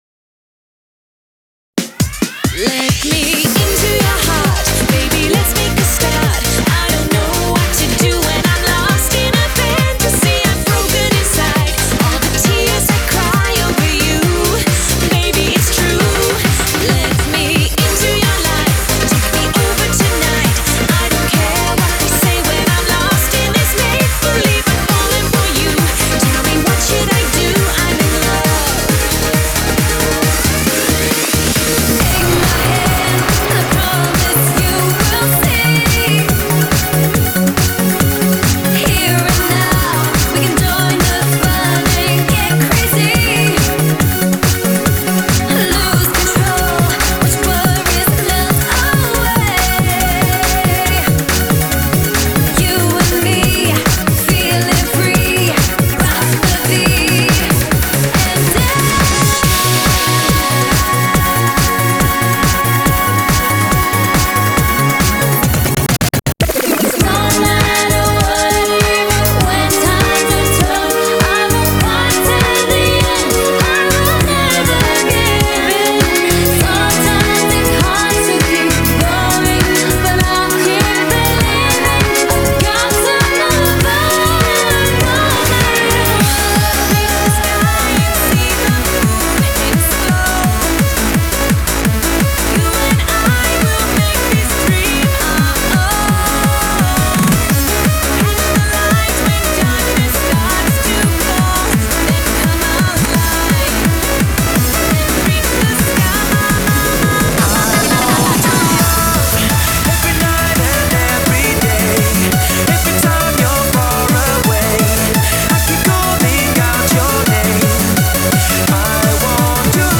BPM100-192